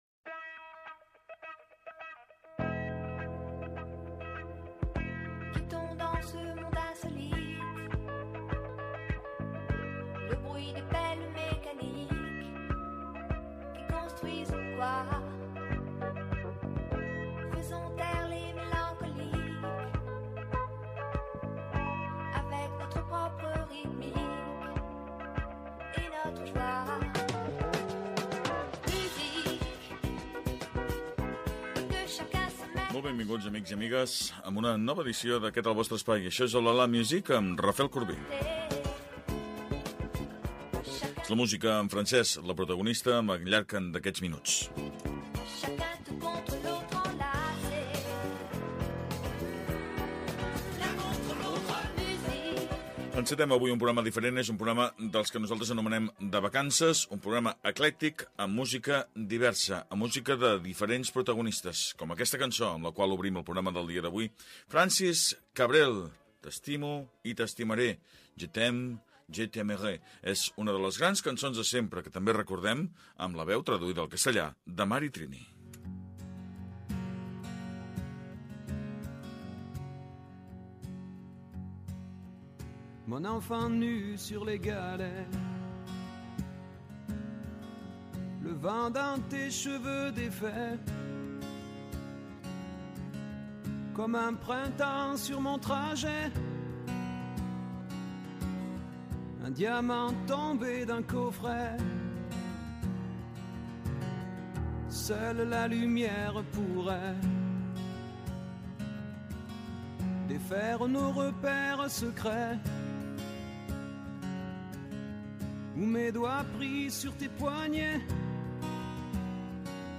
Oh la la, la musique. Programa de música francesa.